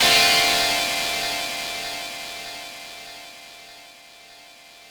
ChordDm7.wav